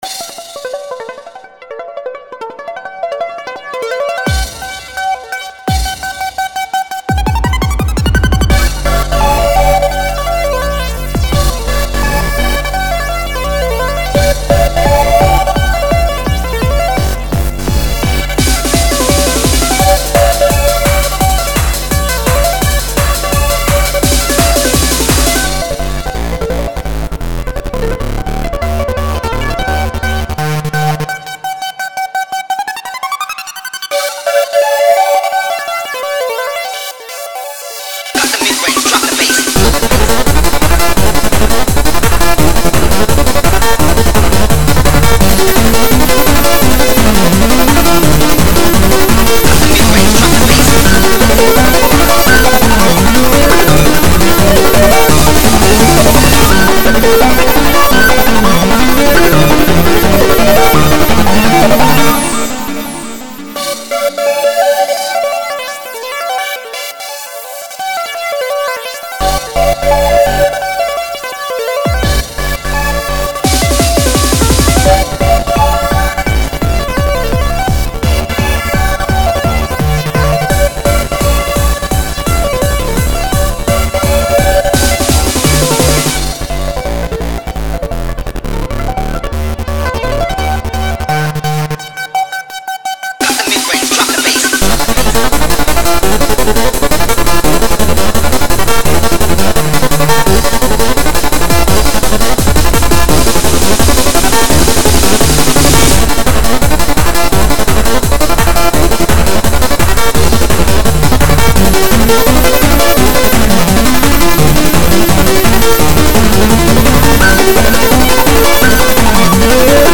An instrumental recording.
This is that remix.